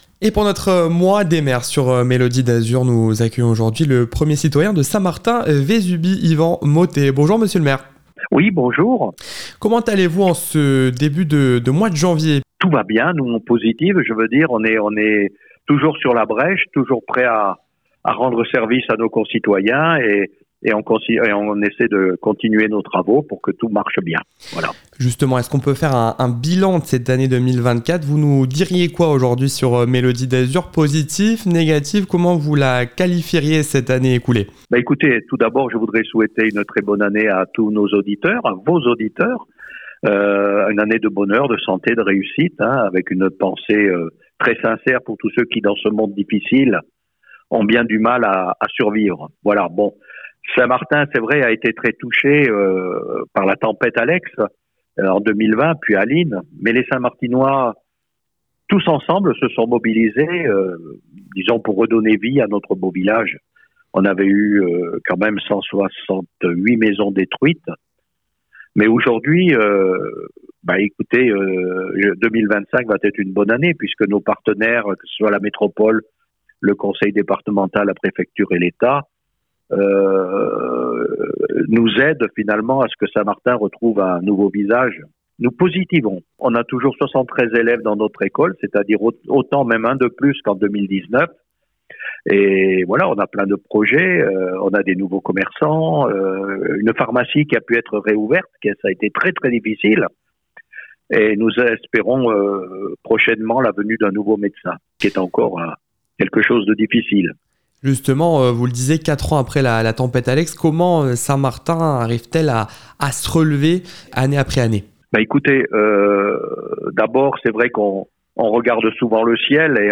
L'équipe de Melody d'Azur a le plaisir de lancer une série d'interviews avec les maires de la Côte d'Azur pour inaugurer cette nouvelle année 2025.
interview-des-maires-episode-4-saint-martin-vesubie-avec-ivan-mottet.wav